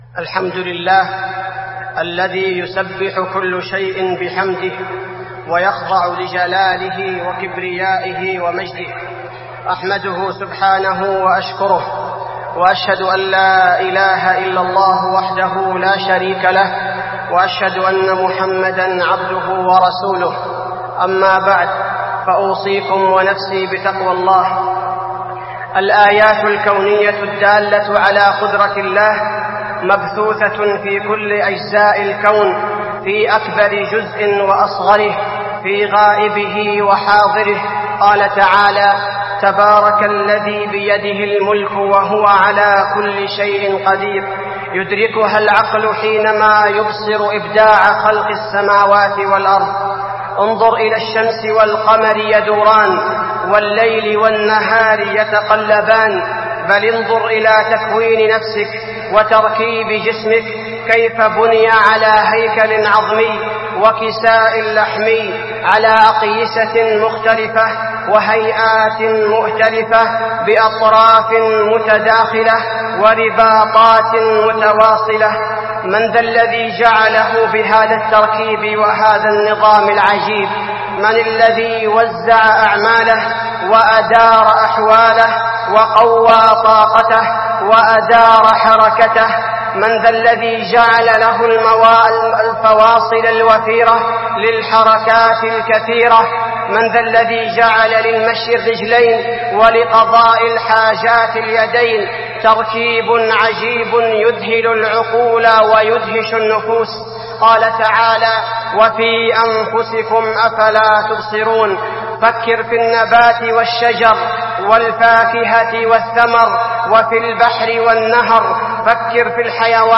خطبة الكسوف المدينة - الشيخ عبدالباري الثبيتي
تاريخ النشر ٢٩ ربيع الأول ١٤٢٤ هـ المكان: المسجد النبوي الشيخ: فضيلة الشيخ عبدالباري الثبيتي فضيلة الشيخ عبدالباري الثبيتي خطبة الكسوف المدينة - الشيخ عبدالباري الثبيتي